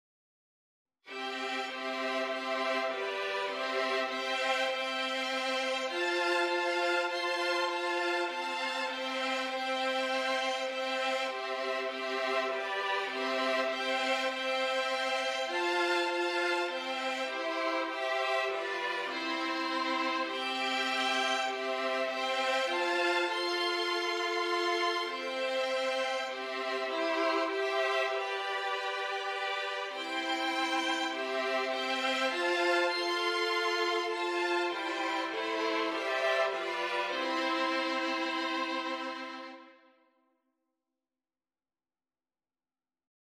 Christian
Violin 1Violin 2Violin 3Violin 4
4/4 (View more 4/4 Music)
Classical (View more Classical Violin Quartet Music)